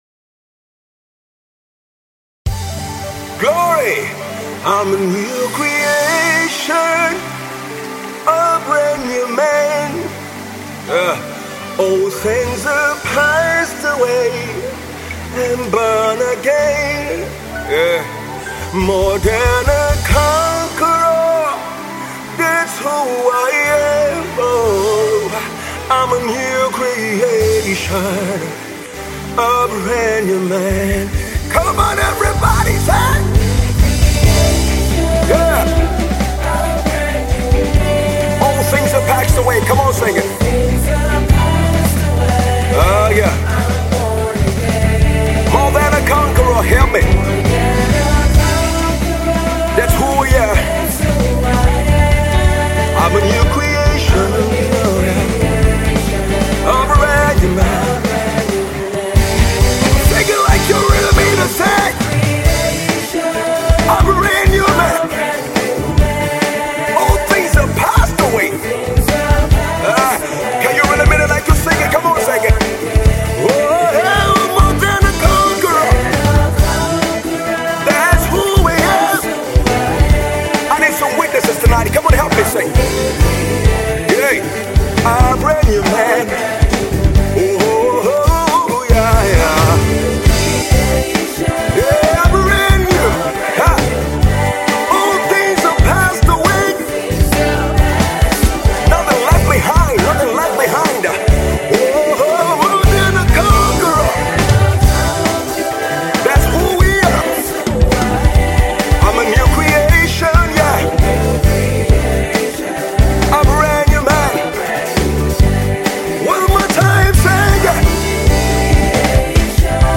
new single